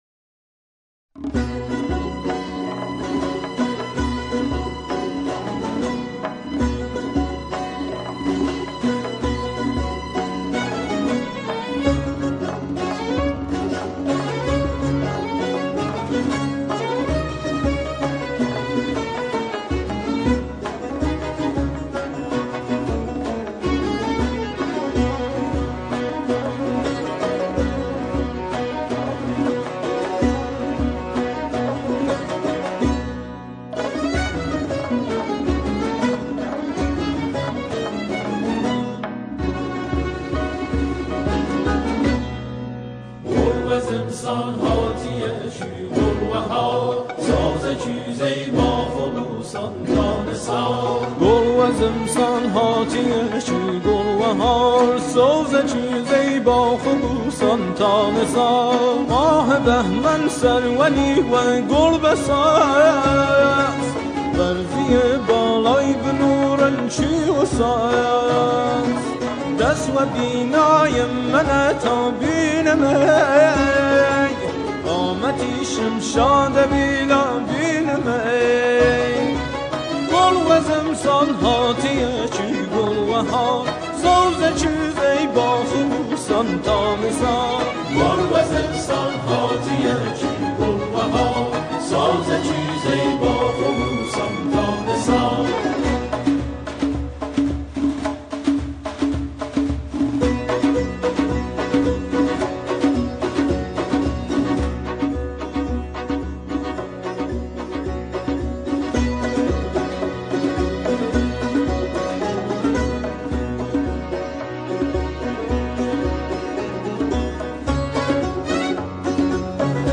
سرودهای دهه فجر
گروهی از جمعخوانان
آنها در این قطعه، شعری را به مناسبت دهه فجر همخوانی می‌کنند.